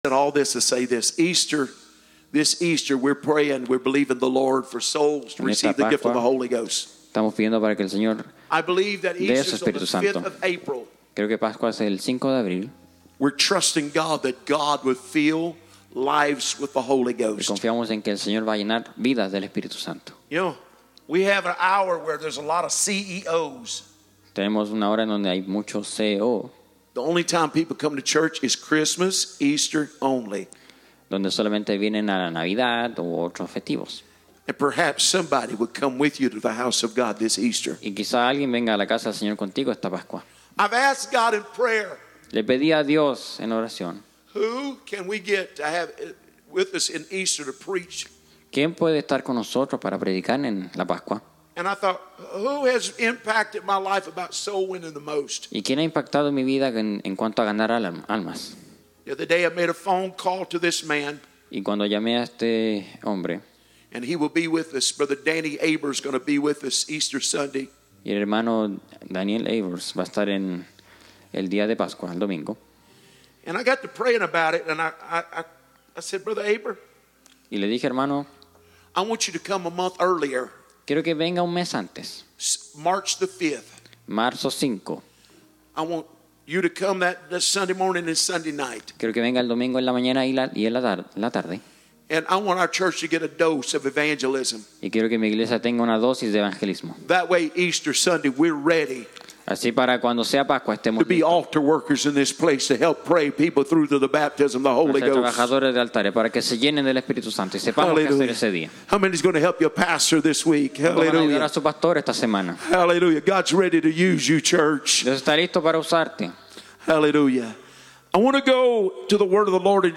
Current Message